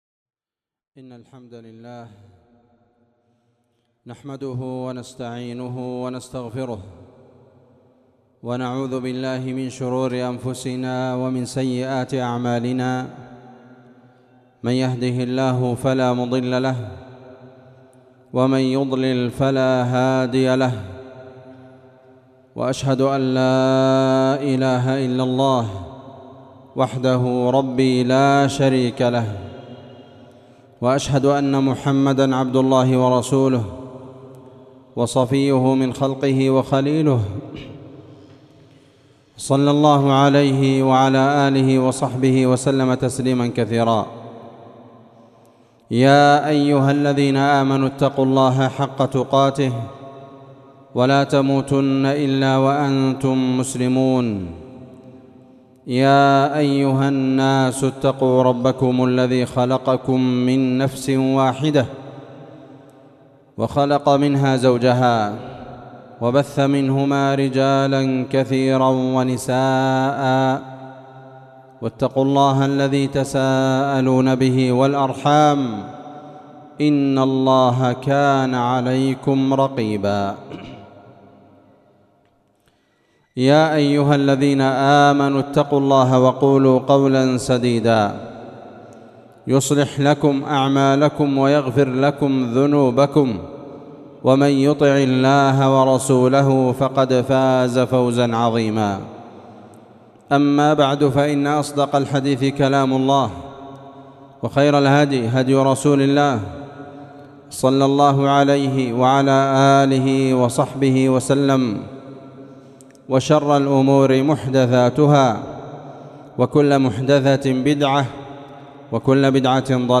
خطبة جمعة بعنوان كشف الغطا عن بعض احكام وحكم فصل الشتا23 جمادى الأولى ر1447ه